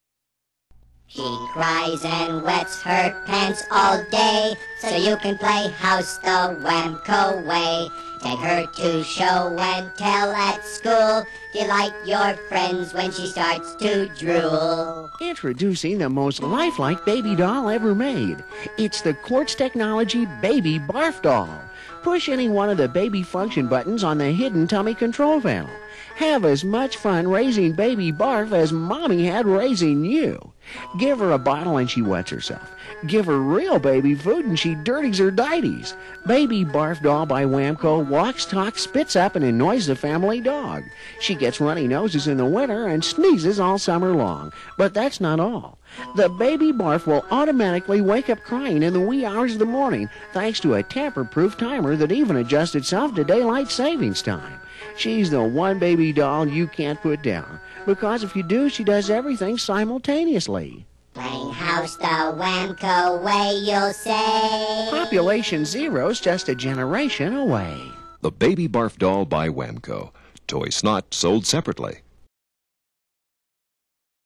He has used the studio recording equipment and software at KONA to dub the LP tracks sans clicks and pops!.